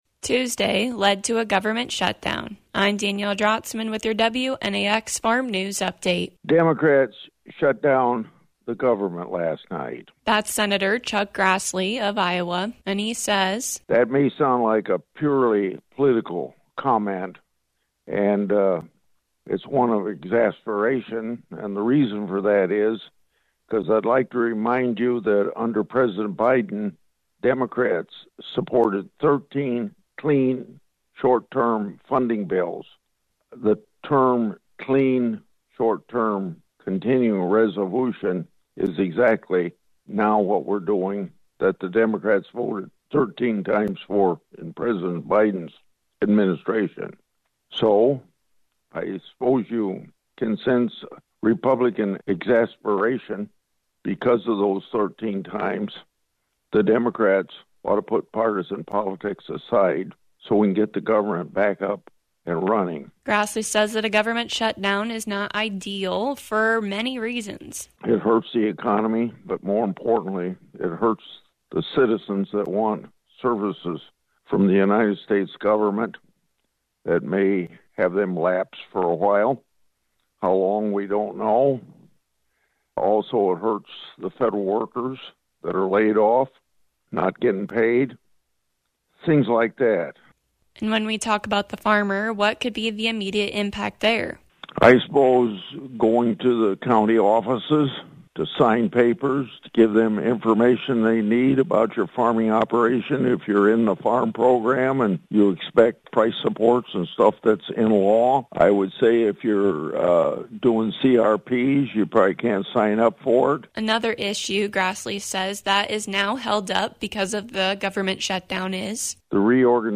The government is shut down. Hear from Senator Chuck Grassley on some initial impacts to agriculture.